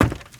High Quality Footsteps
STEPS Wood, Creaky, Run 25.wav